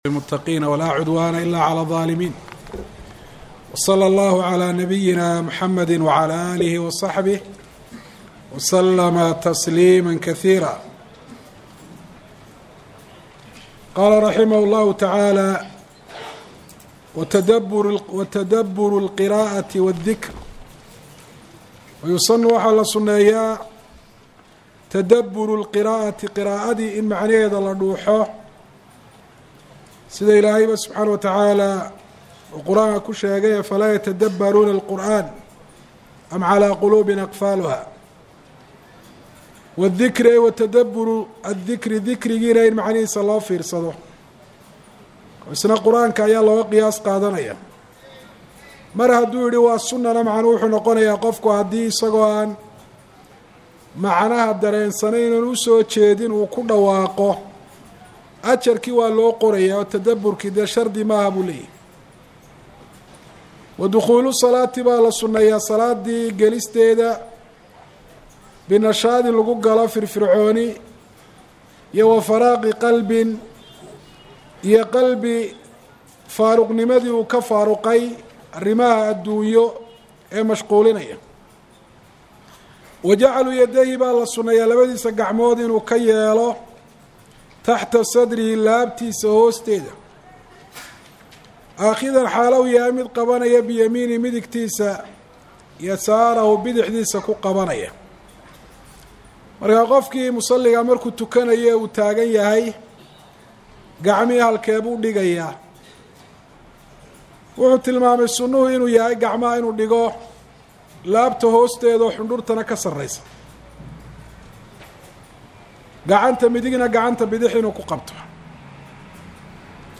Masjid Nakhiil – Hargaisa